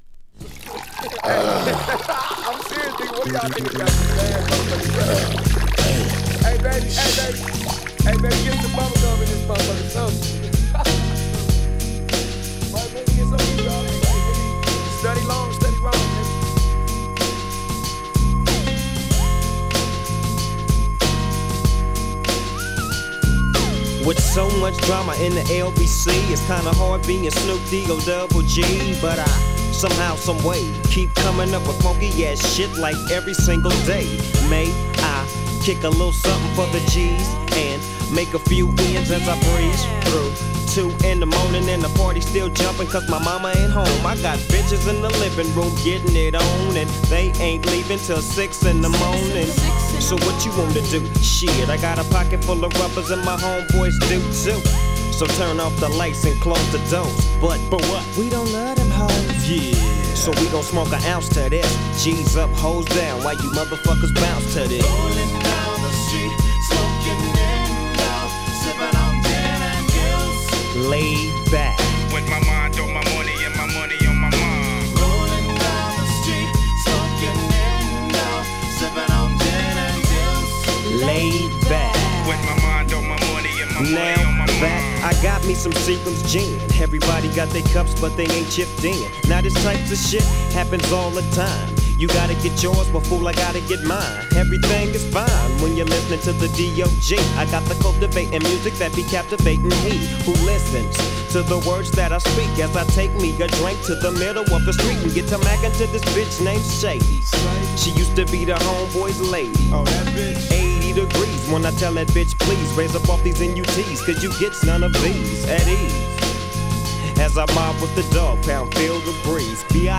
ぶっ太いベースラインも最高にドープなレイドバック・チューン